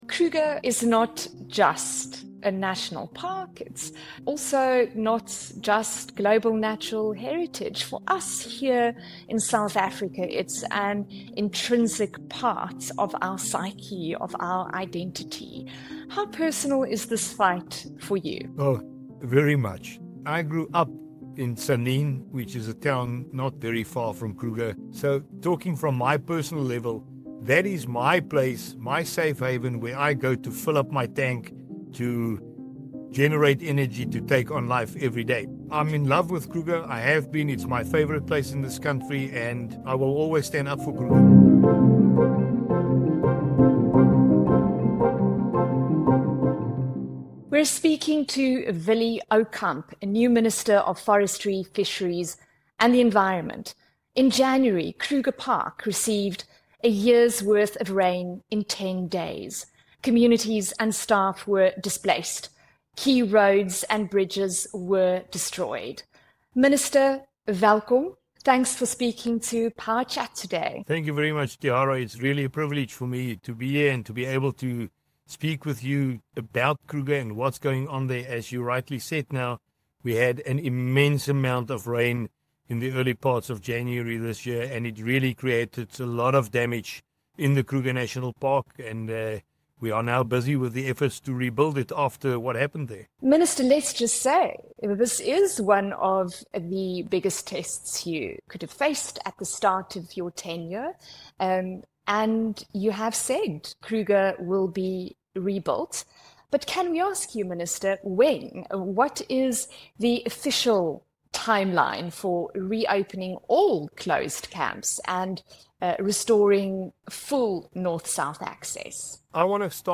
Guest: Minister of Forestry, Fisheries and the Environment, Willie Aucamp